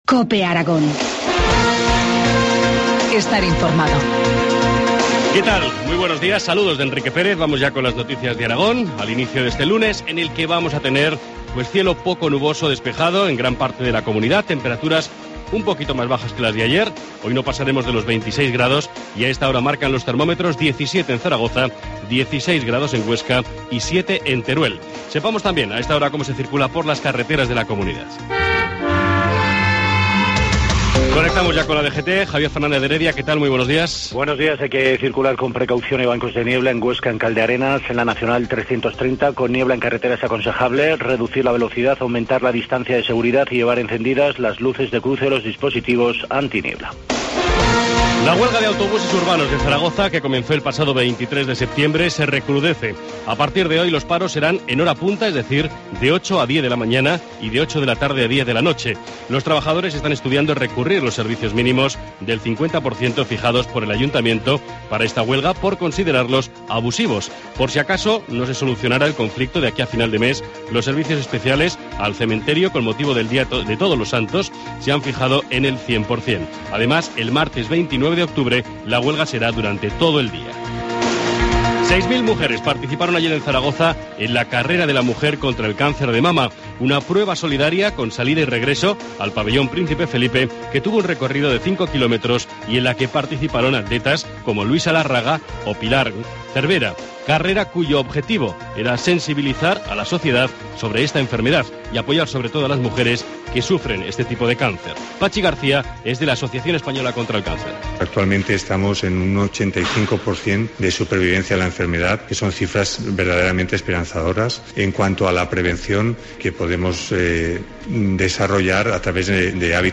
Informativo matinal, lunes 21 de octubre, 7.25 horas